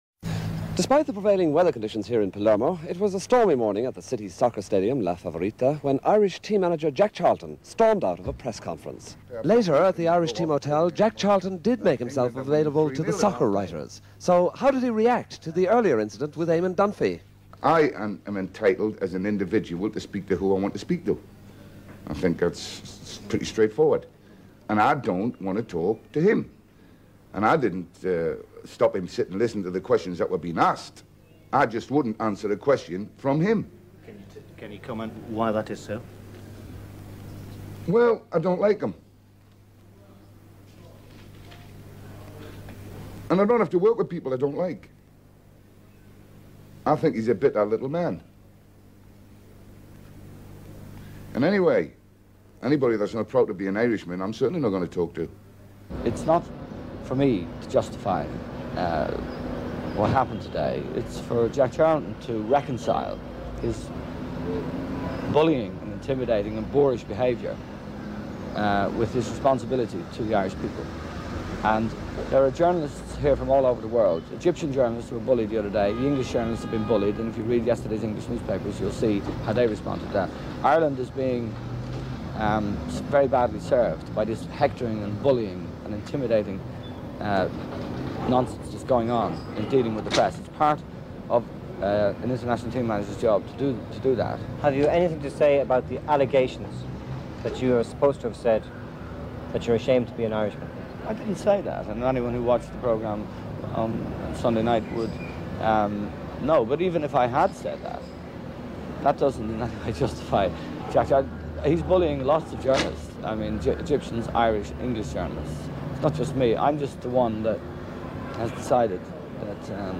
a Mp3 Sound Effect At the World Cup, a major row broke out at the Irish camp in Palermo when the Irish Manager Jack Charlton stormed out of a news conference after refusing to answer questions from Eamon Dunphy. Report shows to camera Colm Murray. Jack Charlton at later press conference saying that he does not want to talk to or answer Eamon Dunphy, doesn't like him, he is a 'bitter little man'. Interview with Eamon Dunphy, on his reaction to Jack Charlton's behaviour. Irish fans queueing for tickets.